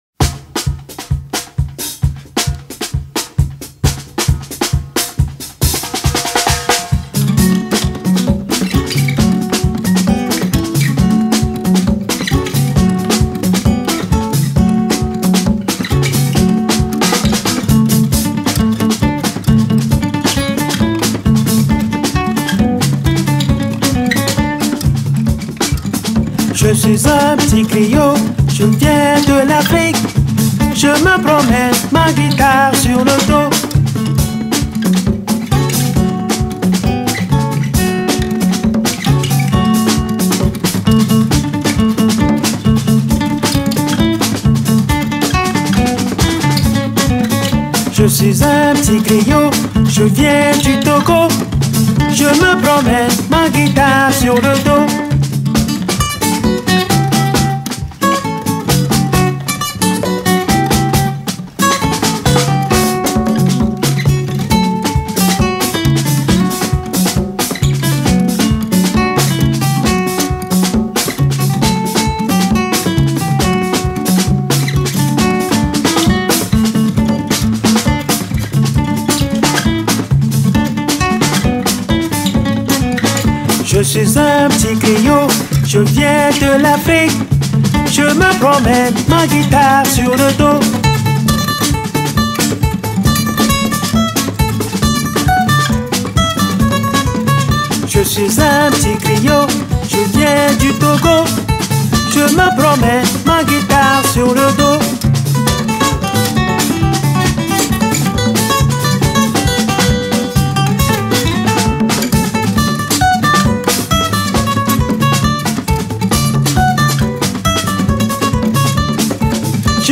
Ame-Tre-Le-Comptine-africaine-pour-enfants-avec-paroles.mp3